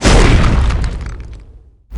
m_woosh_13.wav